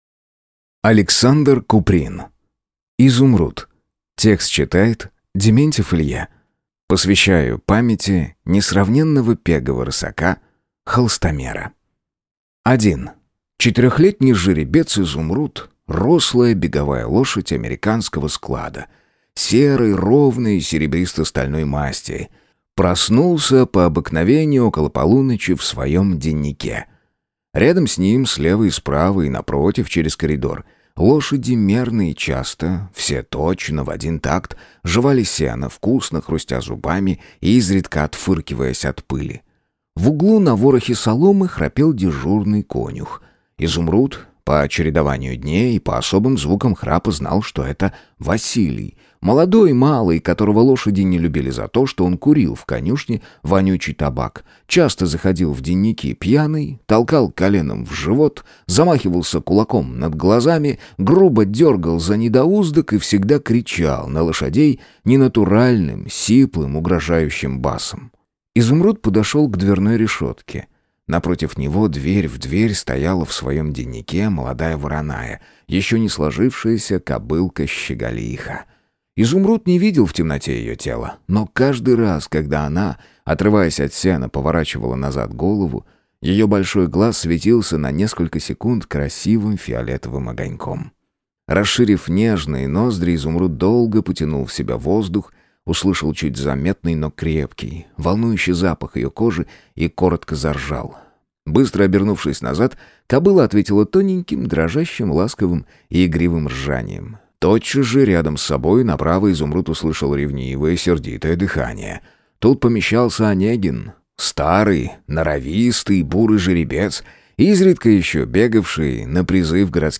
Аудиокнига Изумруд | Библиотека аудиокниг